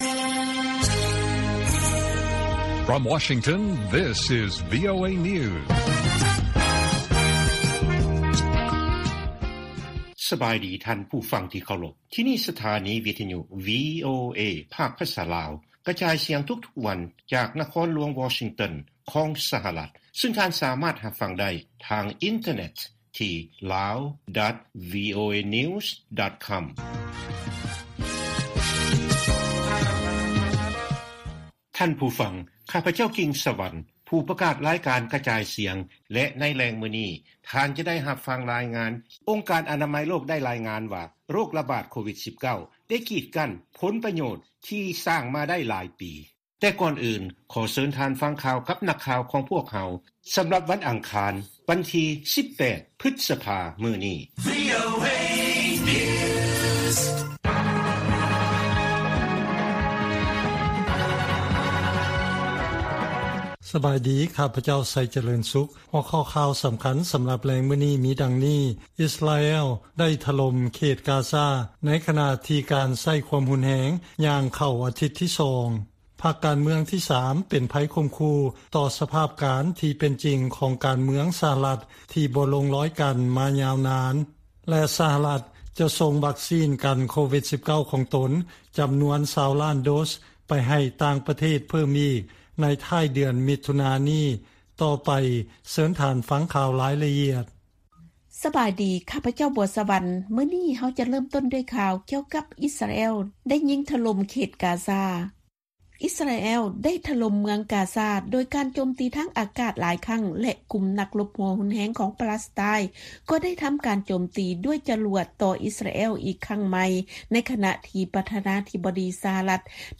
ລາຍການກະຈາຍສຽງຂອງວີໂອເອ ລາວ: ສະຫະລັດ ກຳລັງເພີ່ມການສົ່ງອອກ ຢາວັກຊີນ ກັນໄວຣັສໂຄໂຣນາ ແລະຂ່າວສໍາຄັນອື່ນໆອີກ
ວີໂອເອພາກພາສາລາວ ກະຈາຍສຽງທຸກໆວັນ. ຫົວຂໍ້ຂ່າວສໍາຄັນໃນມື້ນີ້ມີ: 1) ‘ພັກການເມືອງທີສາມ’ ເປັນໄພຂົ່ມຂູ່ ຕໍ່ສະພາບການທີ່ເປັນຈິງ ຂອງການເມືອງສະຫະລັດ ທີ່ບໍ່ລົງລອຍກັນ ມາຍາວນານ.